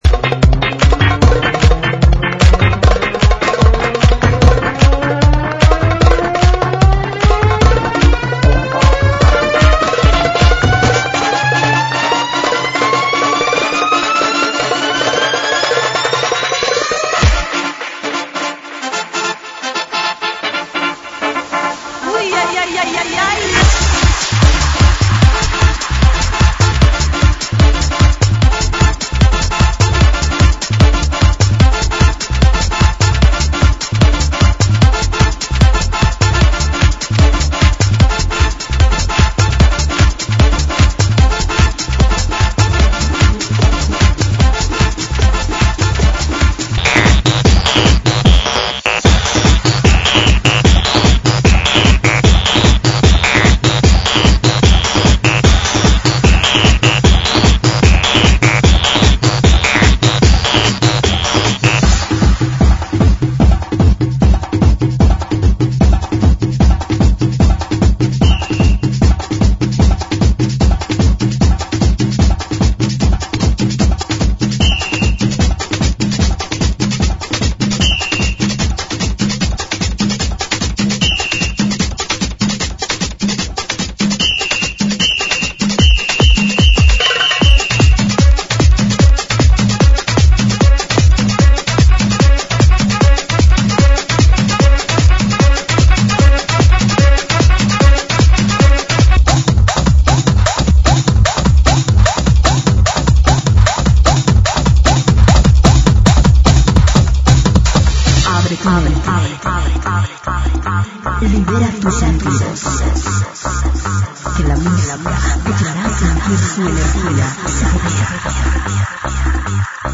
GENERO: LATINO – TRIBAL
AEROBICS (STEP-HILOW)